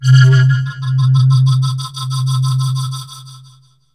AMBIENT ATMOSPHERES-1 0006.wav